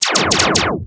Lasers.wav